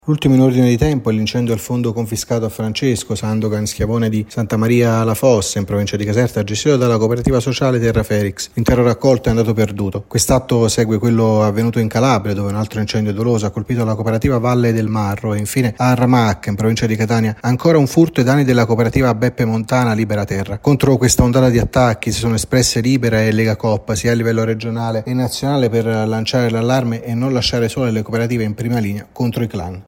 Al Sud un’ondata di attacchi ai beni e terreni confiscati alle mafie. Il servizio